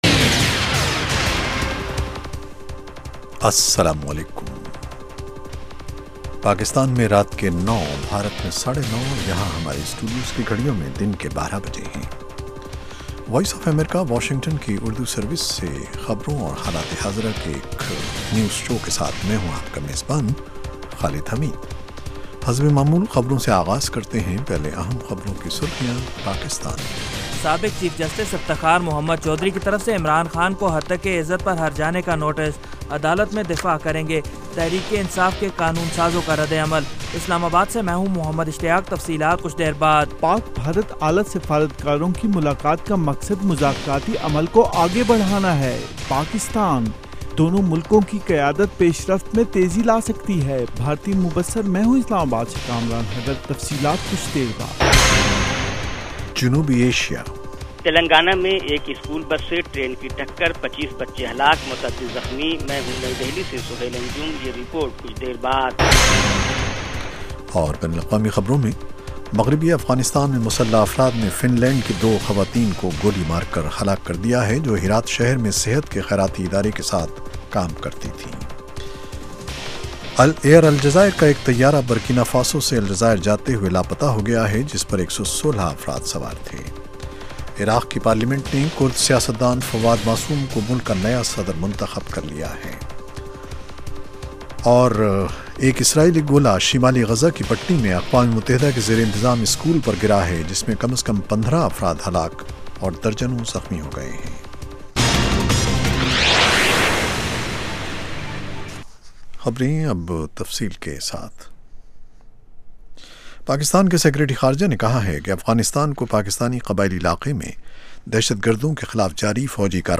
اس کے علاوہ انٹرویو، صحت، ادب و فن، کھیل، سائنس اور ٹیکنالوجی اور دوسرے موضوعات کا احاطہ۔